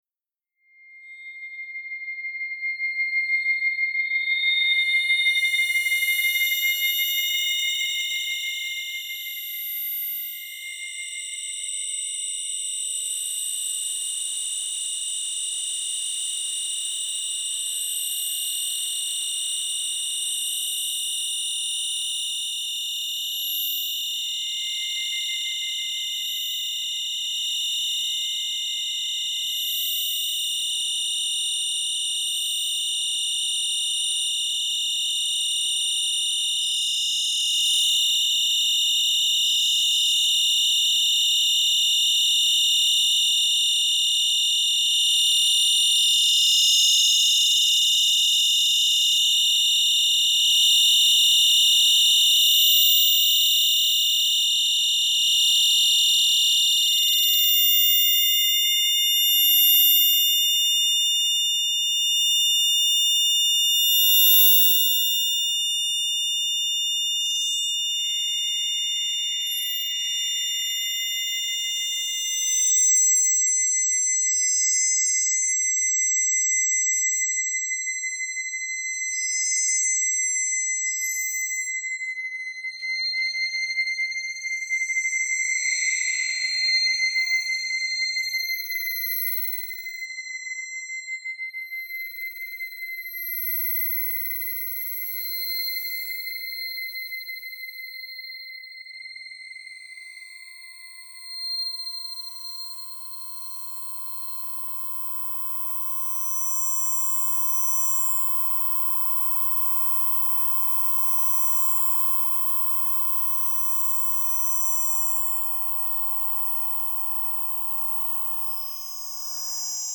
A HIF.L. session often consists of a warm up period of exercise, followed by three to ten repetitions of high frequency listening exercise, separated by medium intensity exercise for recovery and ending with a period of cool down…